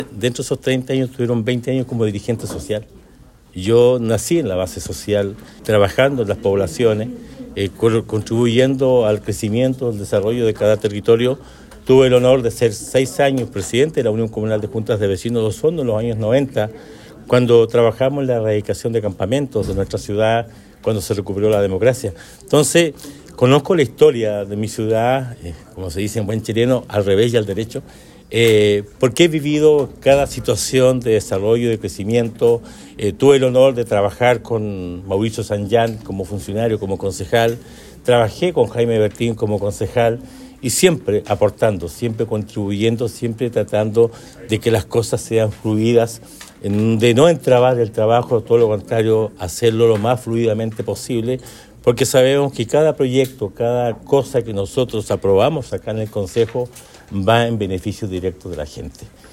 En sesión ordinaria de Concejo Municipal realizada este martes 12 de noviembre, el alcalde de Osorno, Emeterio Carrillo Torres, presentó la renuncia a su cargo, la que se hará efectiva a contar del próximo sábado 16 de noviembre.